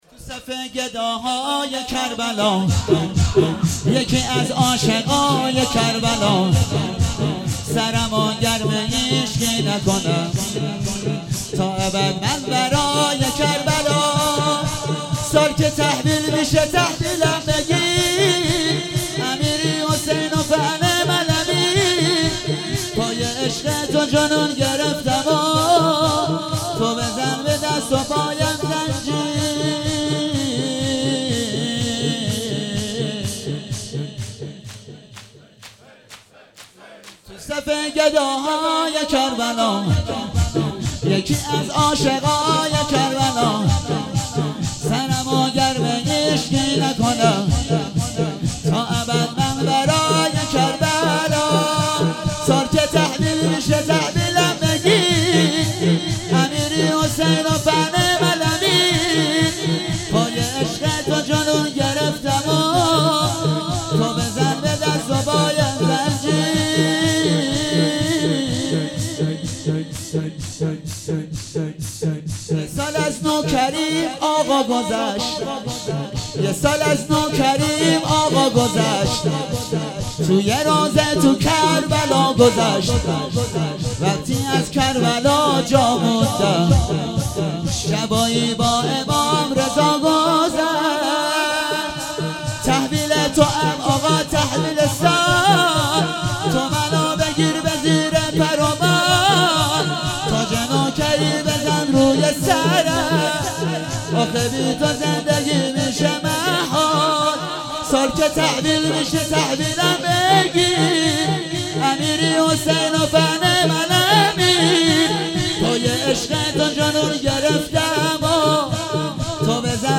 شور - تو صف گداهای کربلام